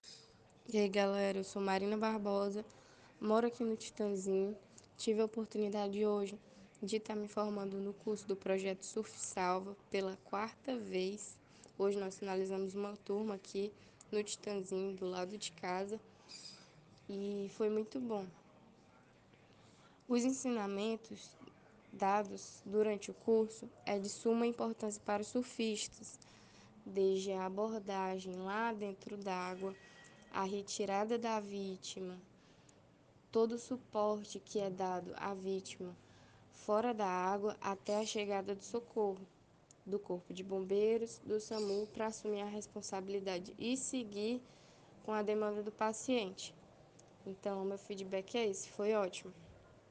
Áudio da surfista